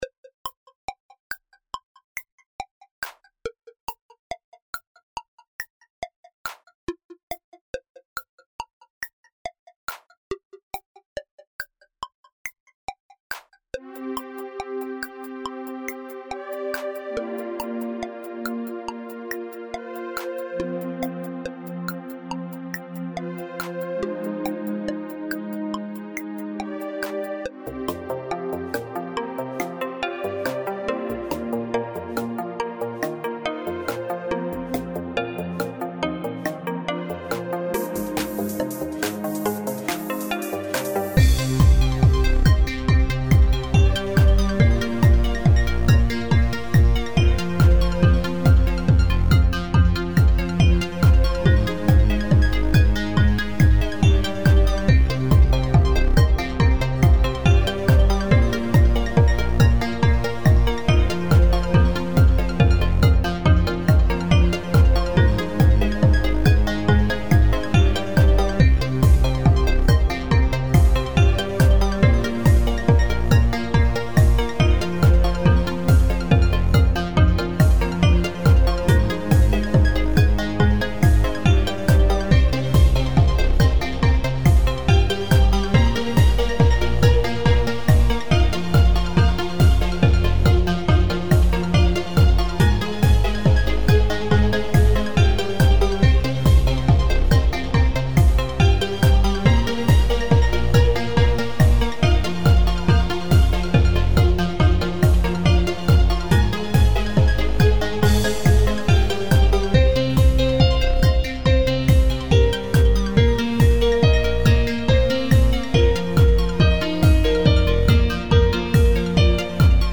24.52 Получилось нечто похожее на лёгкое муз. сопровождение к игре в пинг-понг :-)) Послушайте и почувствуйте азарт!!!